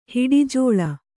♪ hiḍi jōḷa